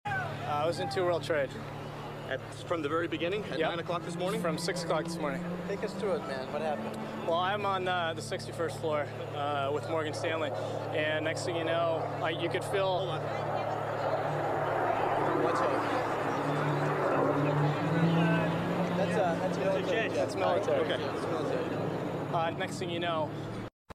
The fear in their eyes and then the relief in their voices as they realize it’s a military jet